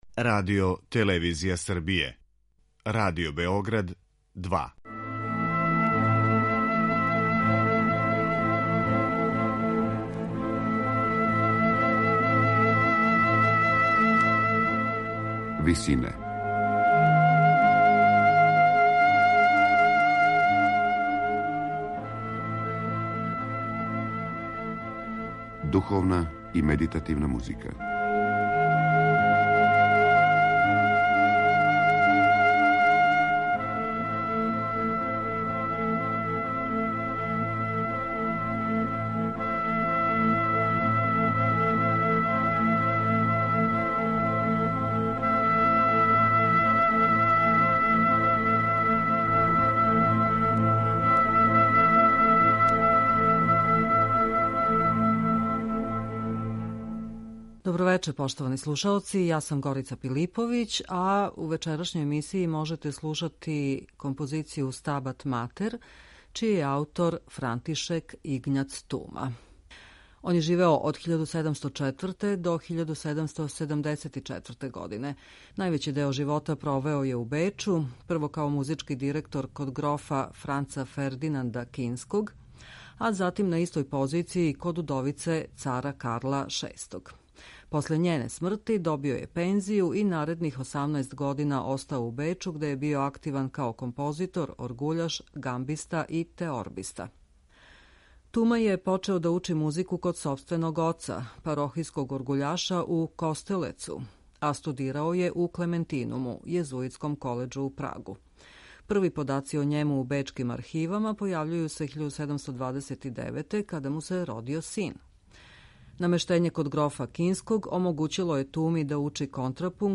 Чешка барокна музика
медитативне и духовне композиције
У вечерашњој емисији слушаћемо обраду за вокални квартет, хор и оргуље.